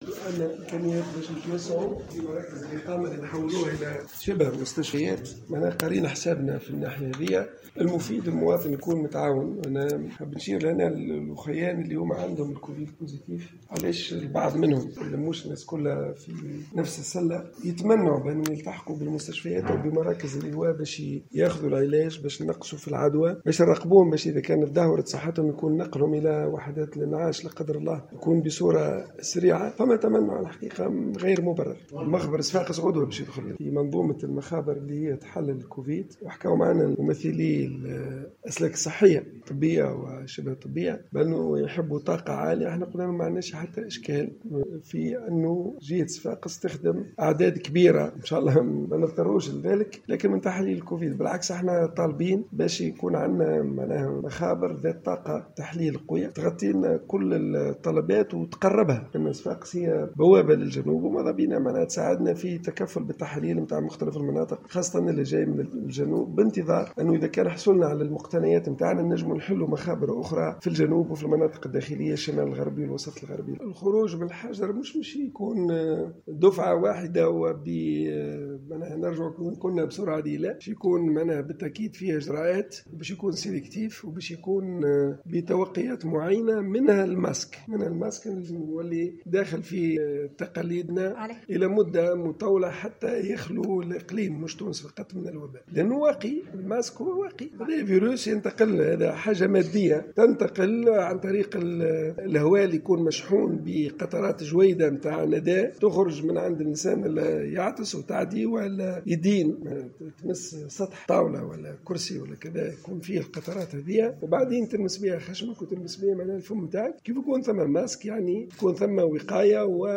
ودعا، في تصريح اعلامي على هامش إشرافه في صفاقس على جلسة عمل جمعته بمختلف الاطارات الطبية وشبه الطبية وممثلين عن الاتحاد الجهوي للشغل، المصابين المتهربين من الحجر الصحي الى ضرورة التزام بهذا الإجراء، وإلى الالتحاق بالمستشفيات ومراكز الايواء لتلقي العلاج اللازم والخضوع للمراقبة من أجل الحد من انتشار فيروس "كورونا"، وحتّى يتسنى نقلهم بسرعة فائقة الى وحدات الانعاش في حال تدهور صحتهم.